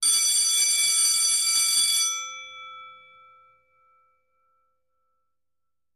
Bells; 9 School Bell.